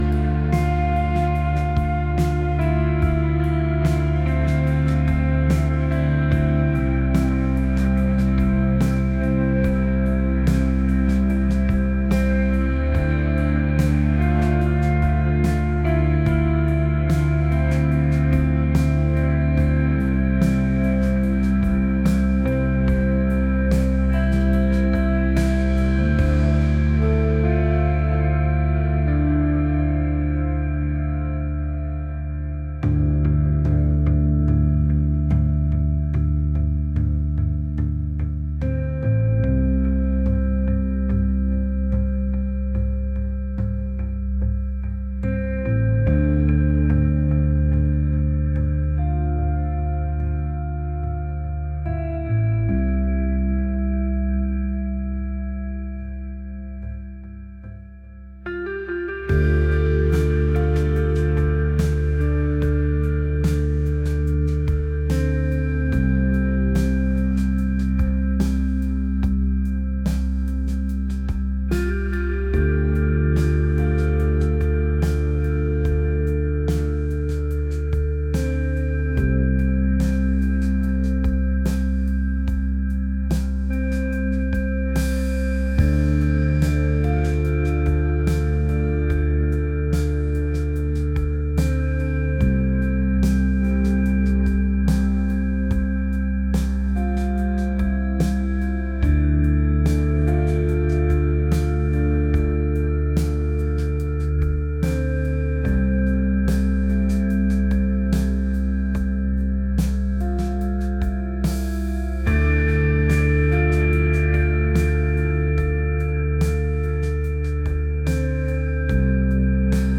atmospheric | rock